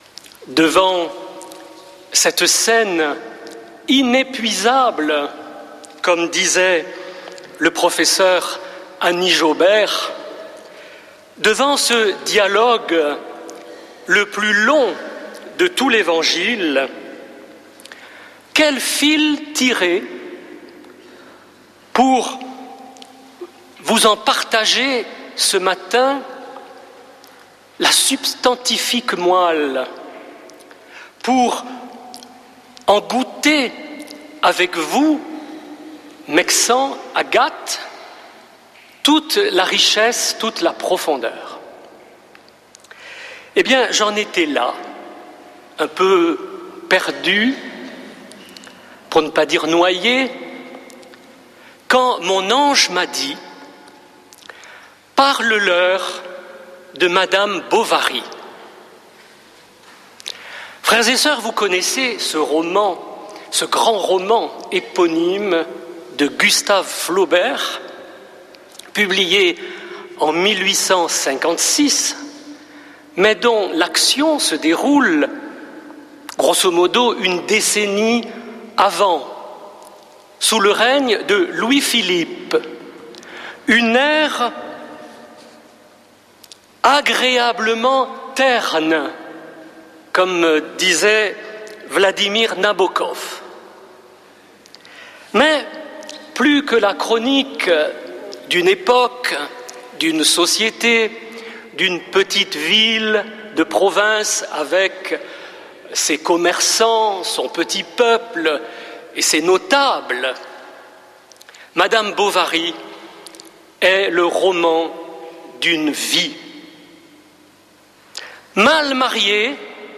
dimanche 8 mars 2026 Messe depuis le couvent des Dominicains de Toulouse Durée 01 h 28 min